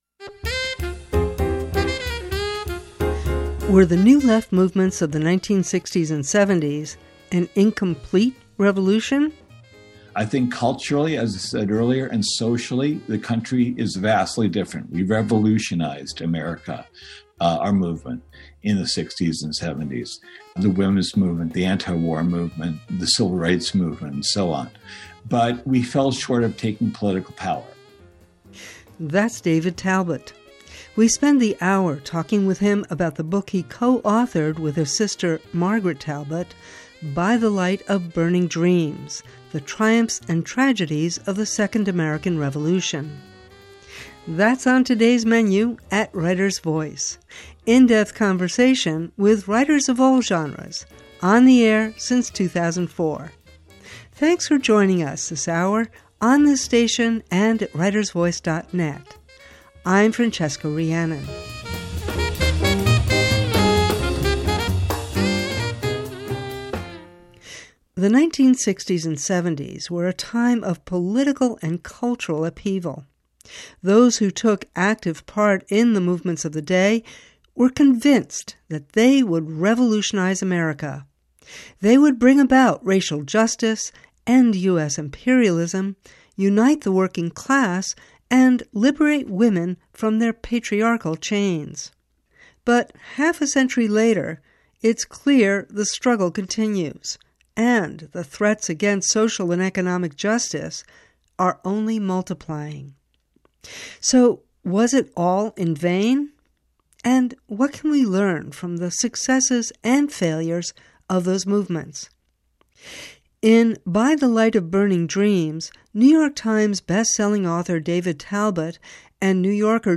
Writers Voice— in depth conversation with writers of all genres, on the air since 2004.